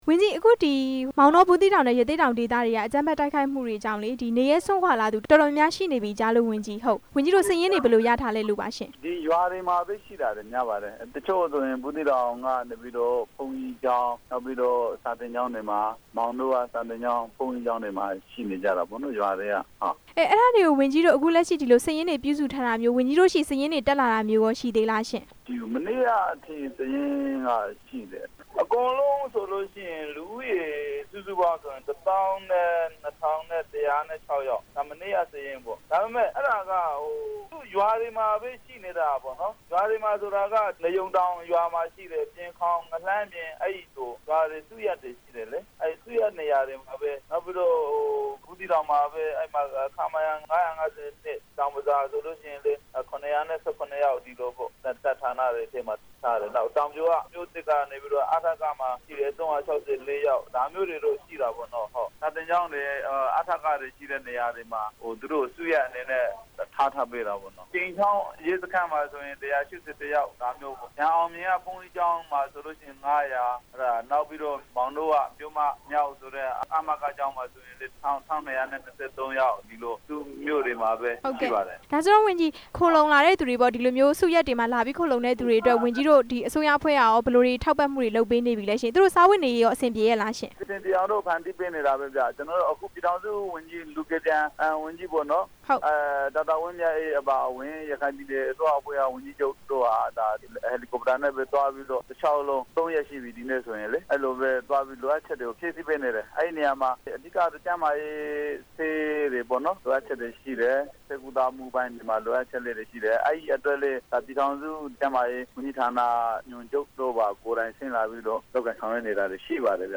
ထွက်ပြေးလာတဲ့ ဒုက္ခသည် အခြေအနေ မေးမြန်းချက်